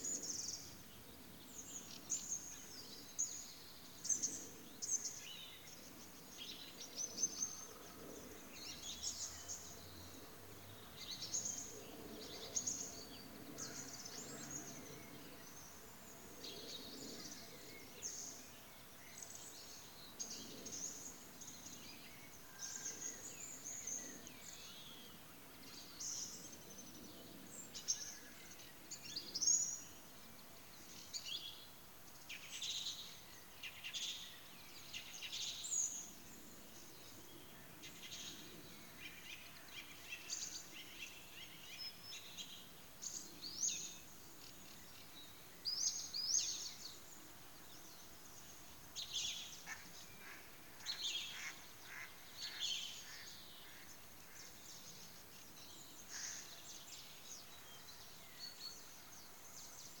contains a one minute recording of bird sounds.
Also notice, that the amplitude it rather small.